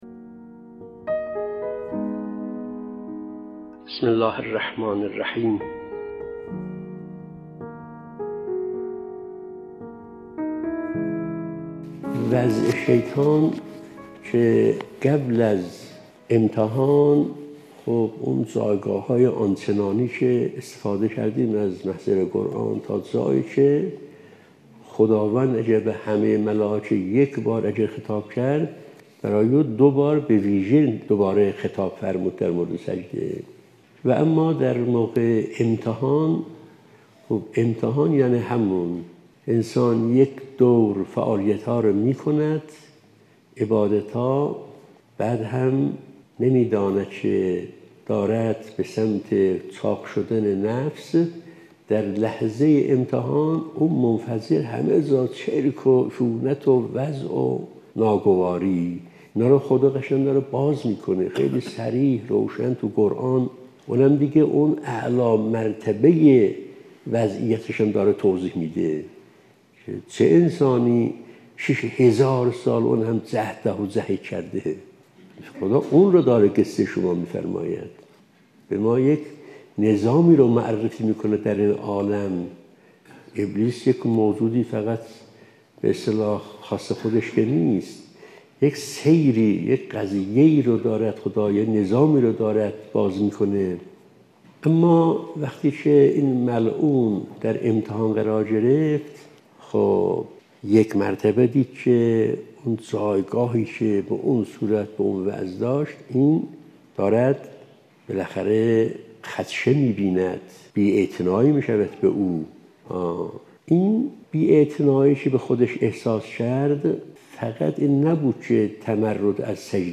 📍از جلسه اولین جمعه ماه قمری| ماه شعبان 🎙ابلیس| بروز انانیت و منیت ابلیس در امتحان 📌شماره(۸) ⏳۵ دقیقه 🔗پیوند دریافت👇 🌐